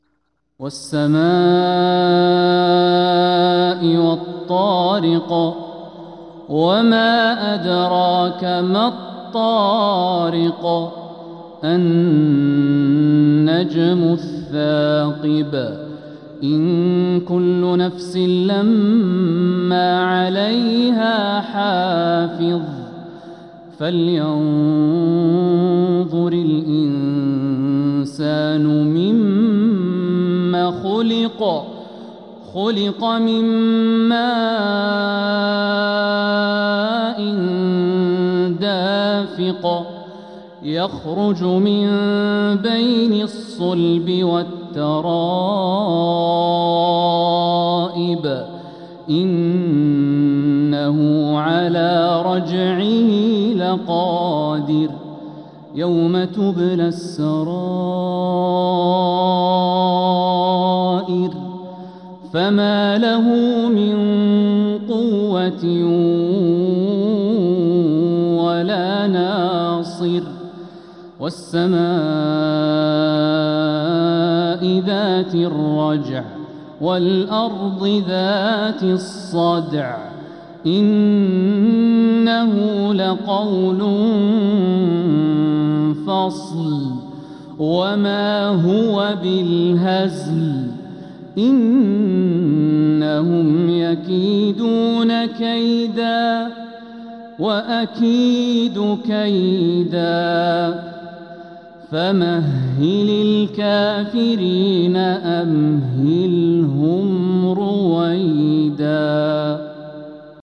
سورة الطارق عشائيات صفر 1447هـ